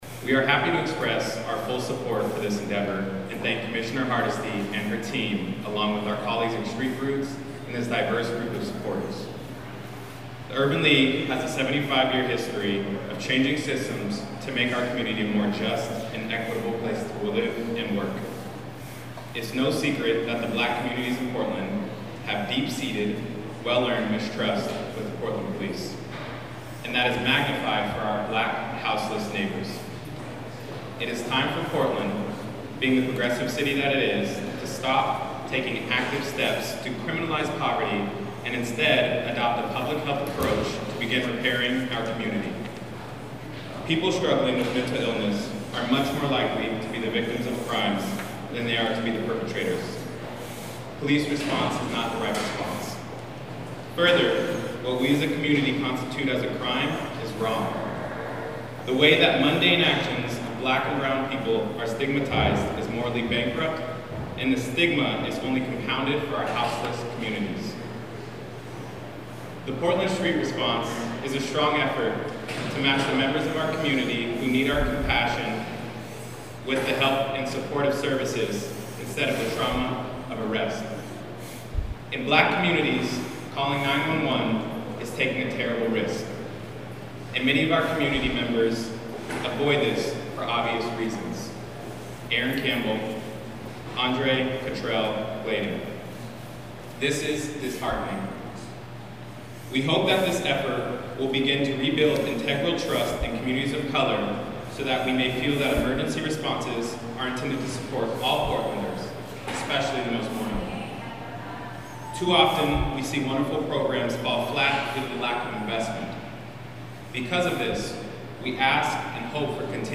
At the press conference, Commissioner Hardesty announced the plan for a pilot program in coordination with Portland State University.